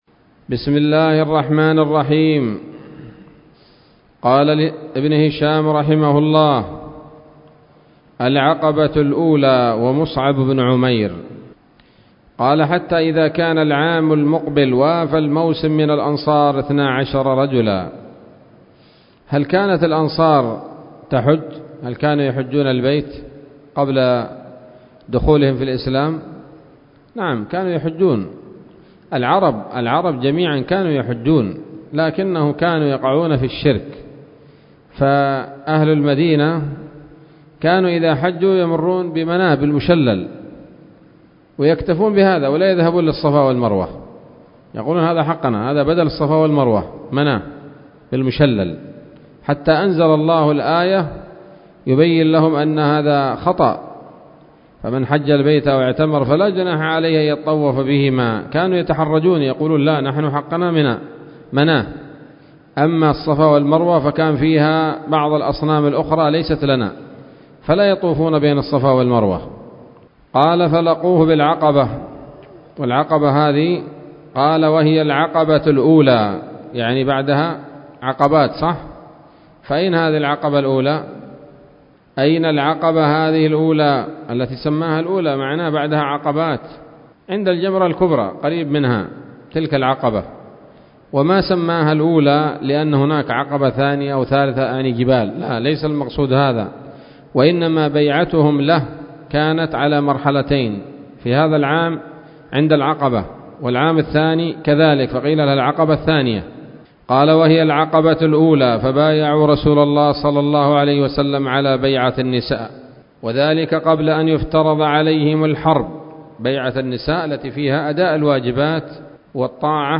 الدرس الستون من التعليق على كتاب السيرة النبوية لابن هشام